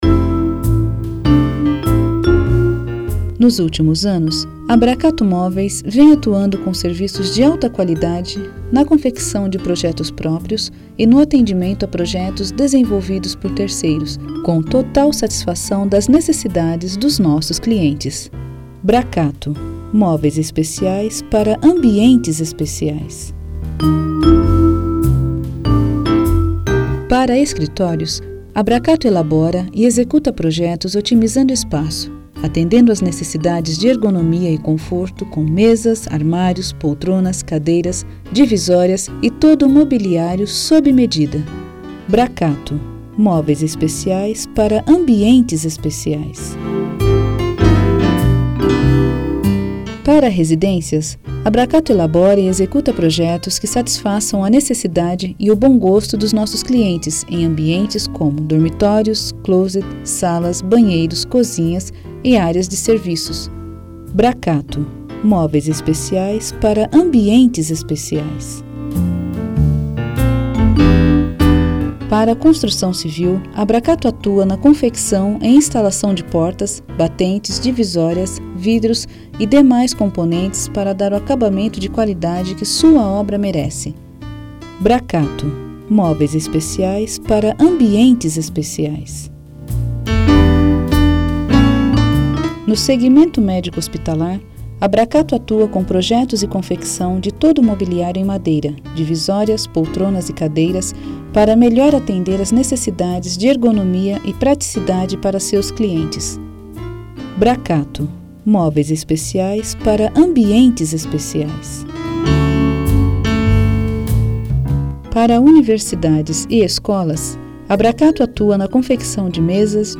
Mensagens de Espera Telefônica - JS Multimídia
Mensagem Institucional de Espera